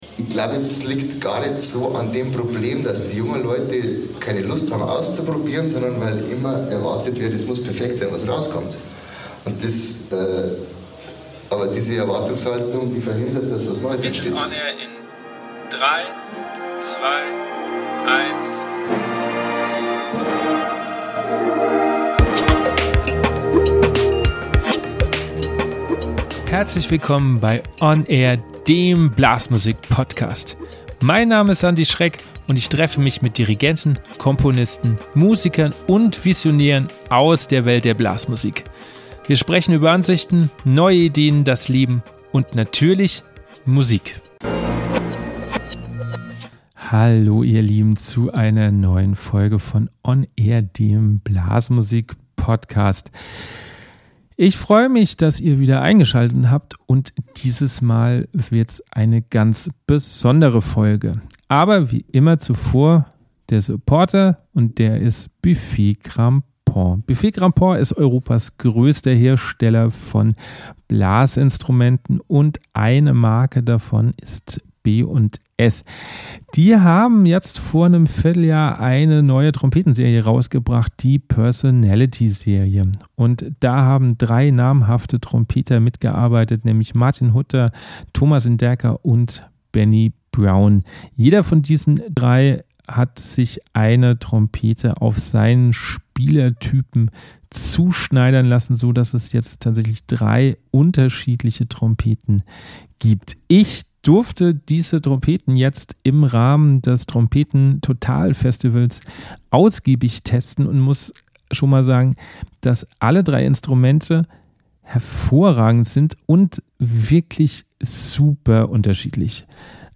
Am 6. Oktober durfte ich im Rahmen des Trompete Total Festivals meinen ersten Podcast vor Live-Publikum machen.